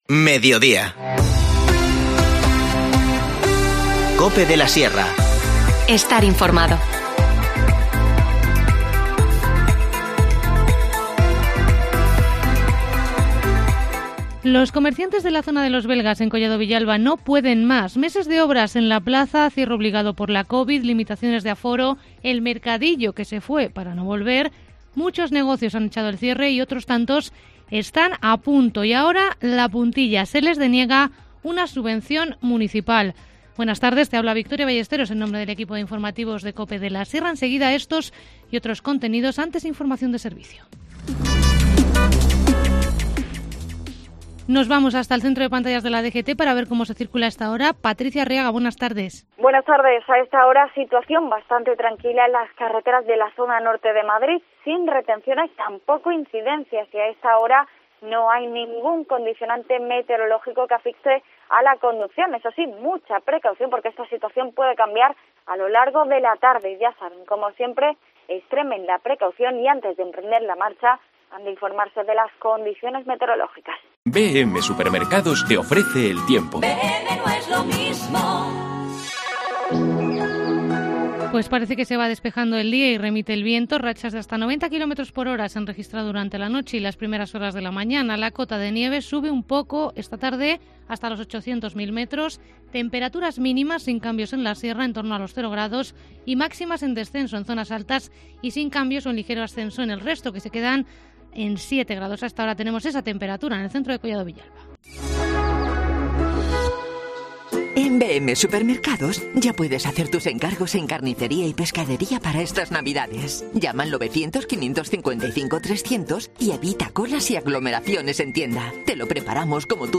Informativo Mediodía 28 de diciembre